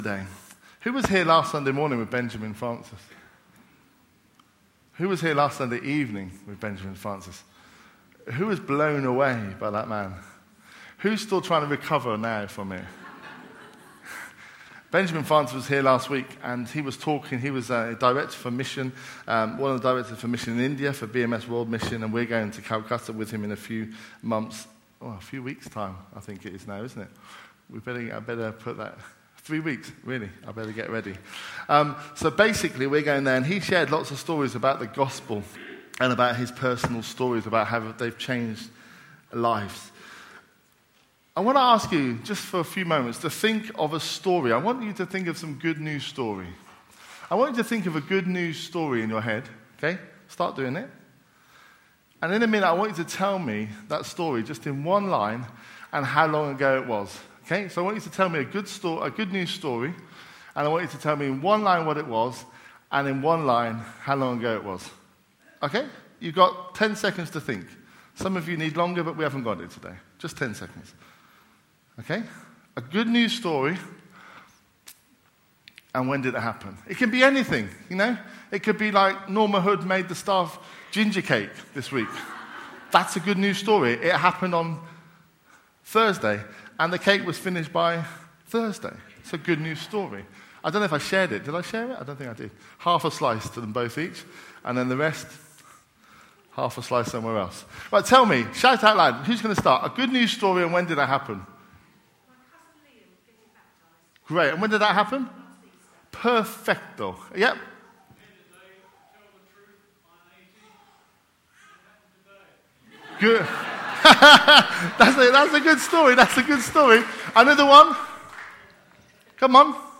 A message from the series "Galatians 1 v3 -9."